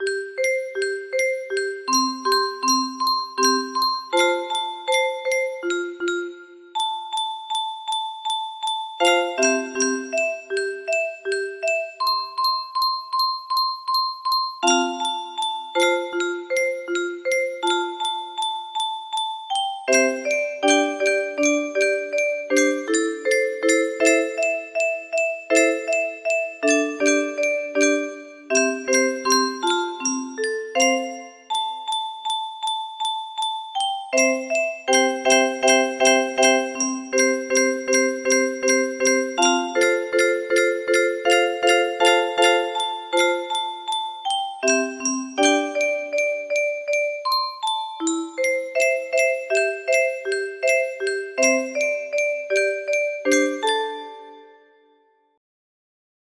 Imported from MIDI from imported midi file (8).mid